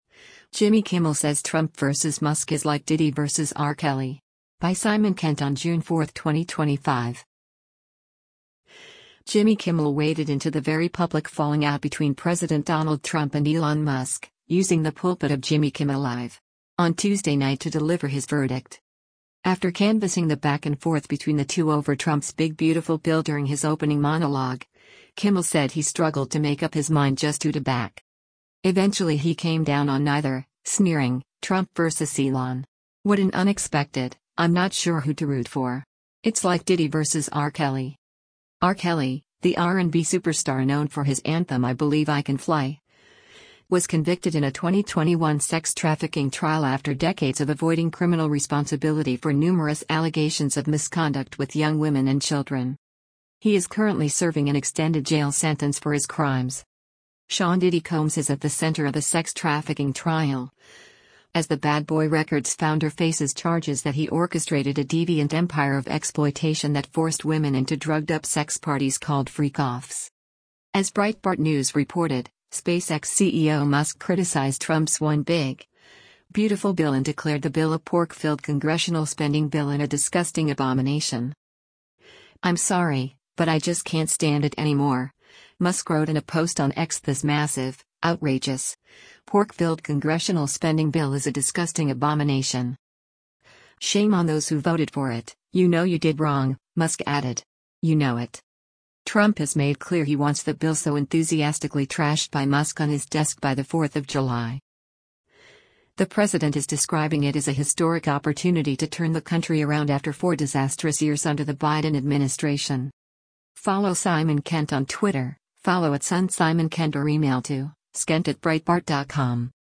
After canvassing the back-and-forth between the two over Trump’s big Beautiful Bill during his opening monologue, Kimmel said he struggled to make up his mind just who to back.